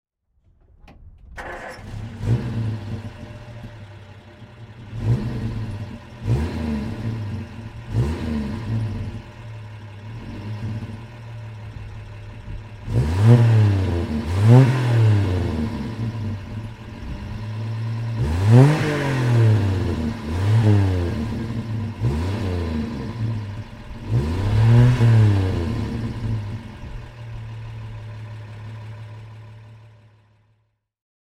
Rover 100 P4 (1959) - Starten und Leerlauf